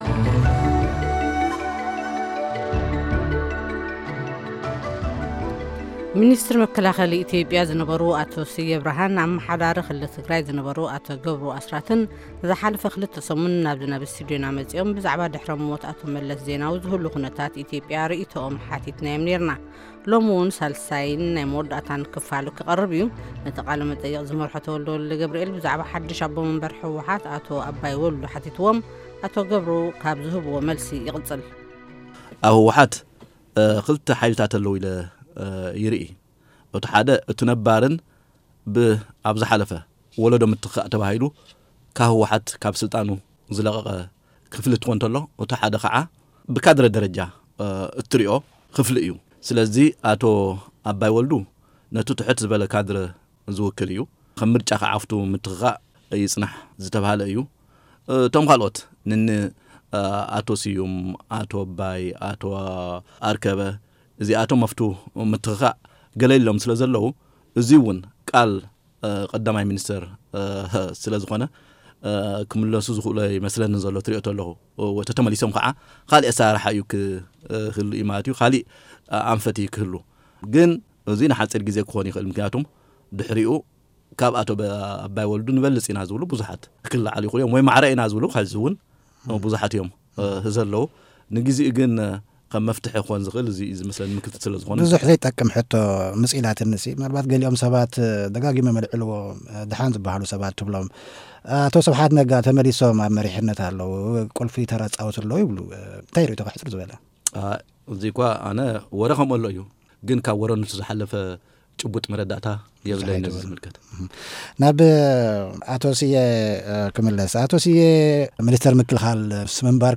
ዛዛሚ ቃለ-ምልልስ ምስ ኣቶ ስየ ኣብራሃን ኣቶ ገብሩ ኣስራትን